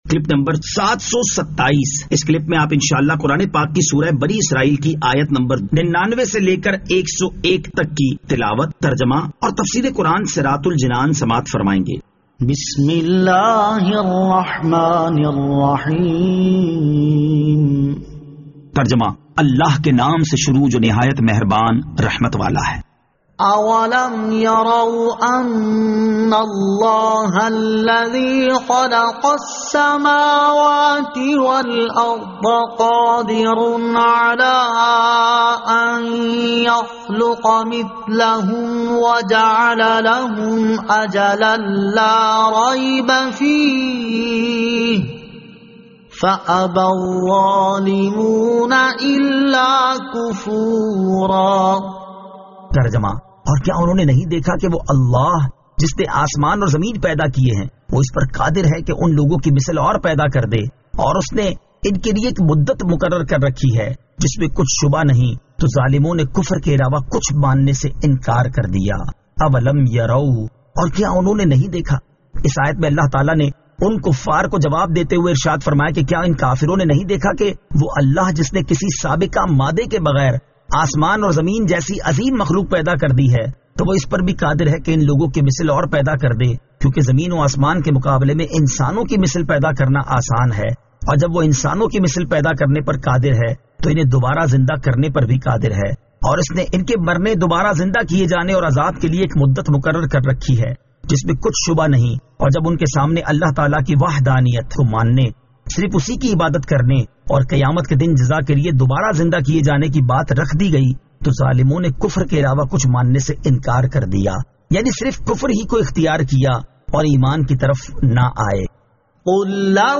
Surah Al-Isra Ayat 99 To 101 Tilawat , Tarjama , Tafseer